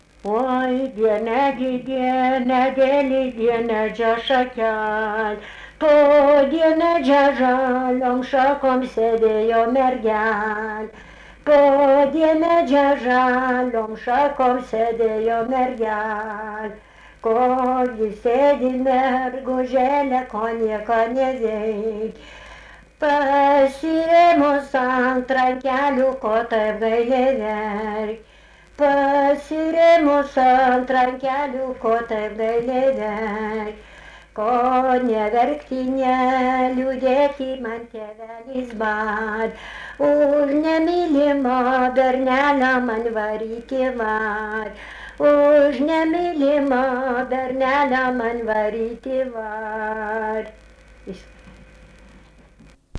Dalykas, tema daina
Erdvinė aprėptis Laibgaliai
Atlikimo pubūdis vokalinis